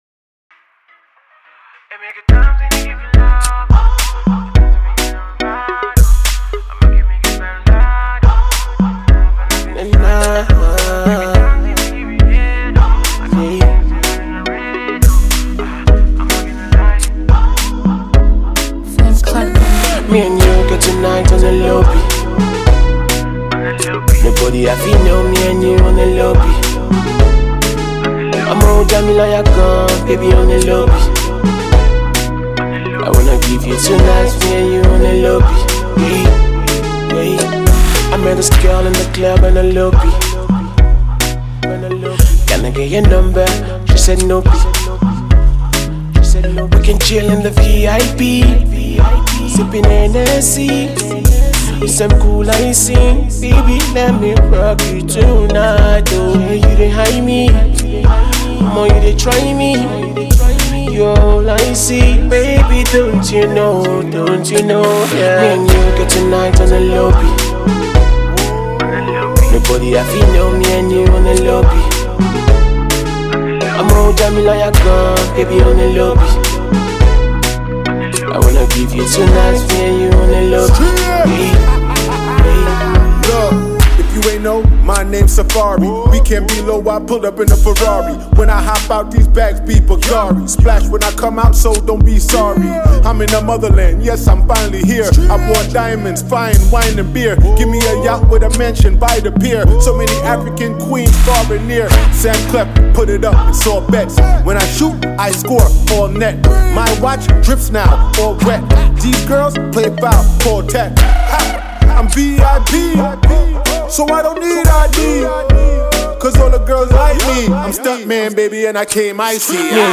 banging tune
American rapper
Afropop